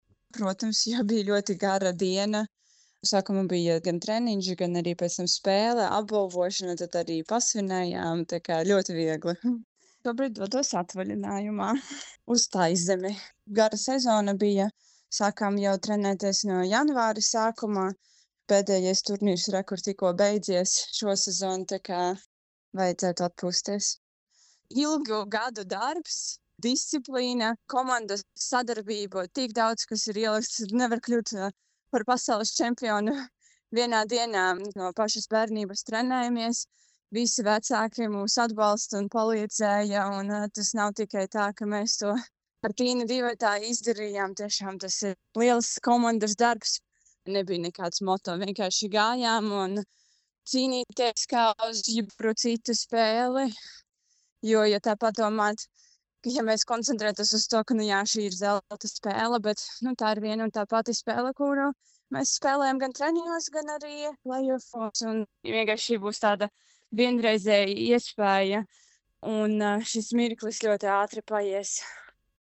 Pēc zelta medaļas izcīnīšanas Anastasija Samoilova Skonto mediju grupai atzina, ka naktī aizmigt, protams, bijis ļoti viegli: